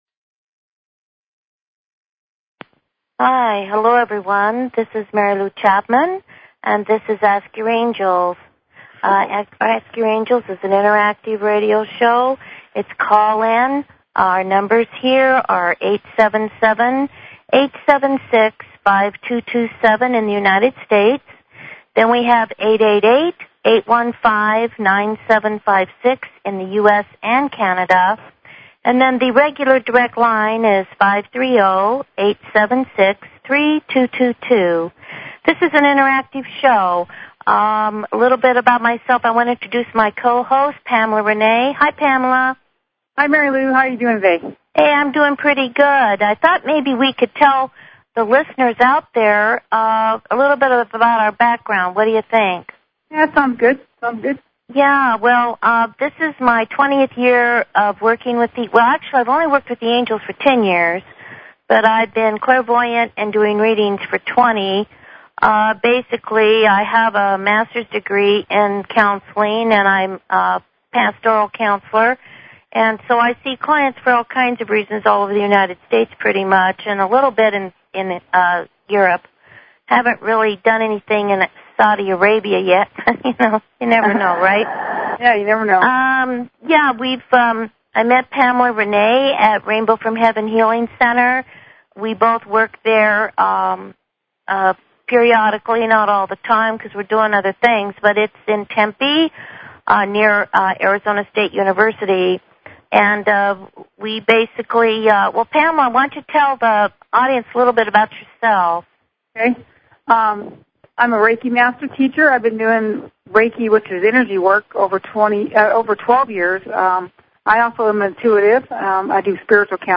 Talk Show Episode, Audio Podcast, Ask_Your_Angels and Courtesy of BBS Radio on , show guests , about , categorized as